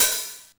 Index of dough-samples/ tidal-drum-machines/ machines/ MPC1000/ mpc1000-oh/
MPC1000_909OHH_TL.wav